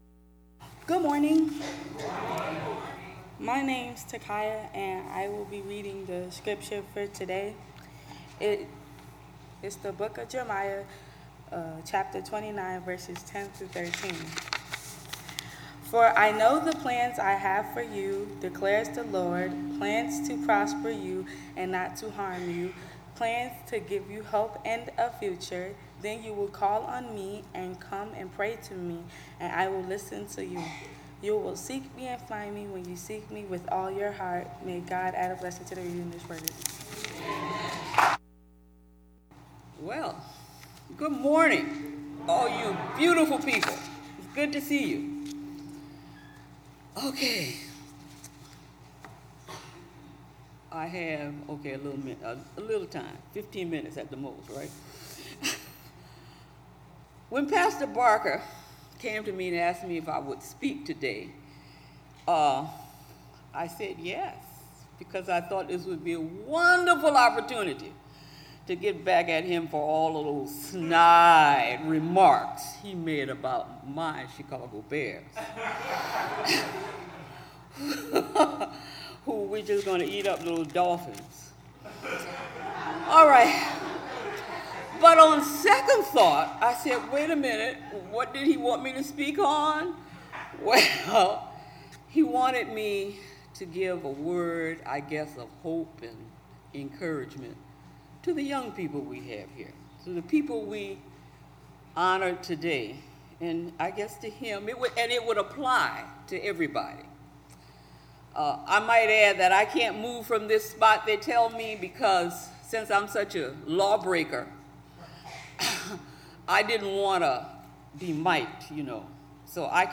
Worship Service 6/25/17